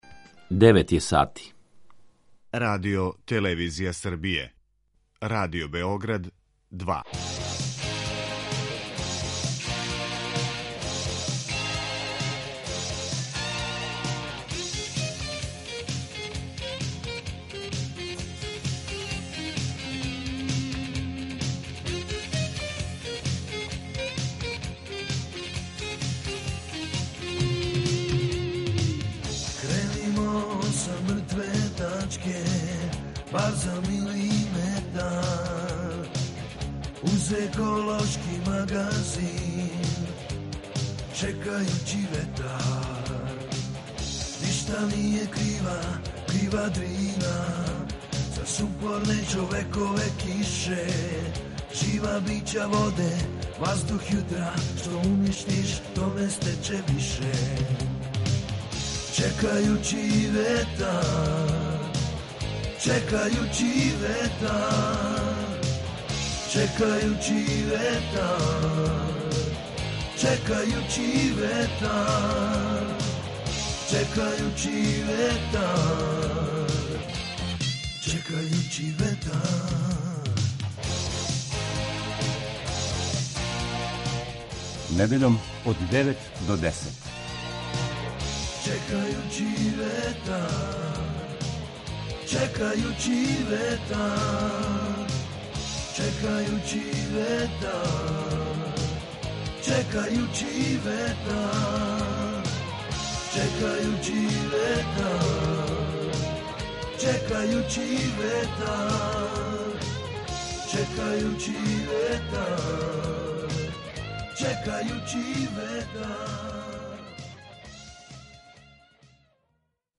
razgovaramo